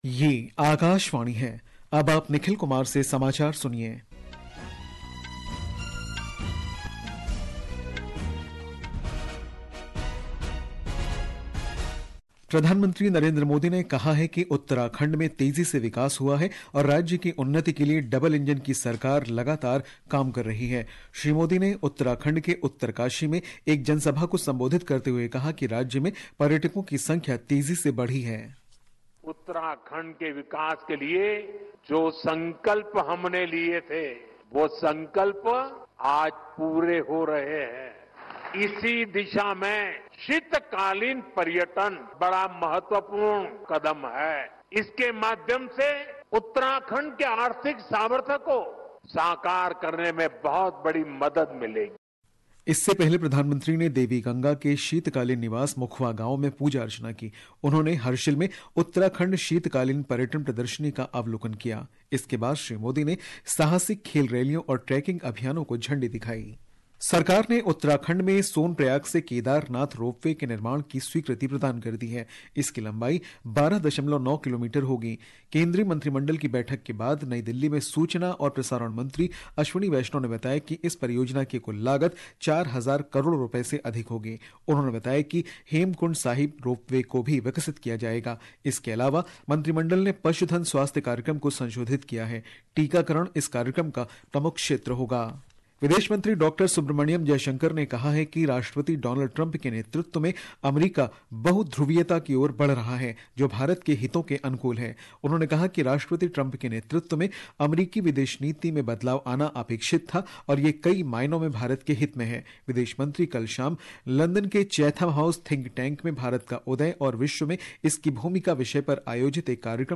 प्रति घंटा समाचार | Hindi